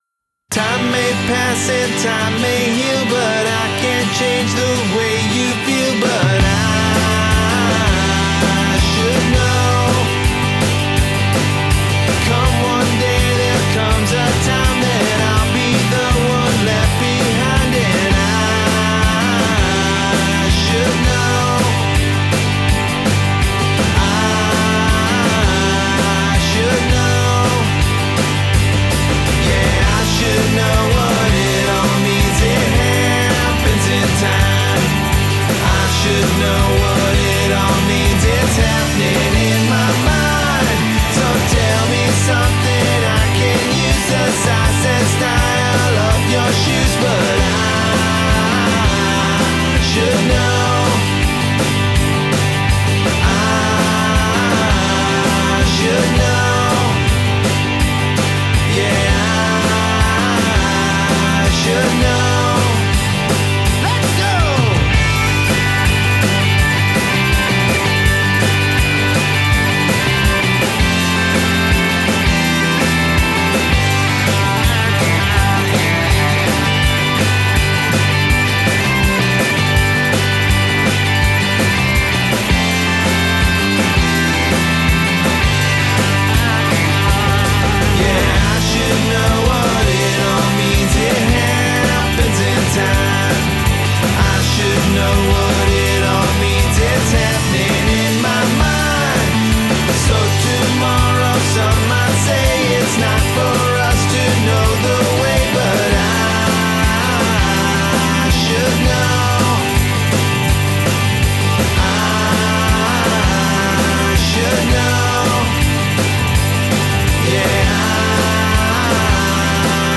driving poprock